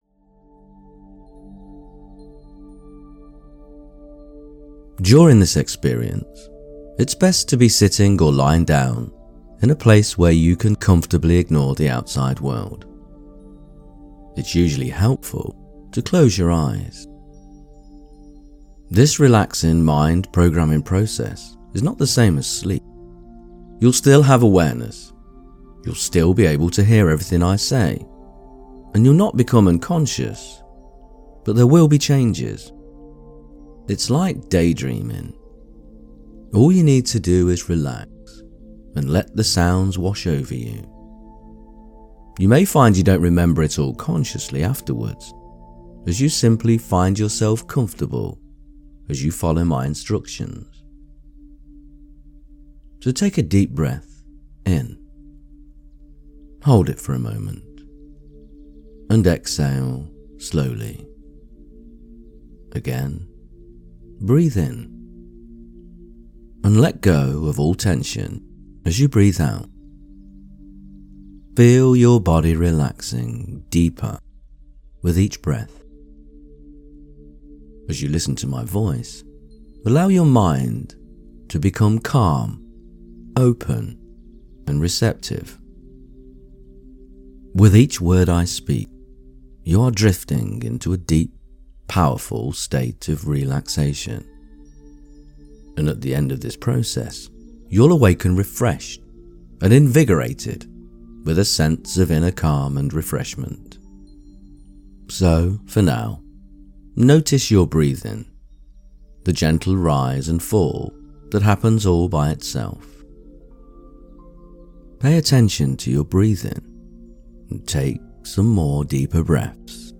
In this soft-spoken journey, we enter your Inner Heart Sanctuary — a sacred garden where love is remembered, not chased.
This guided meditation was created just for you.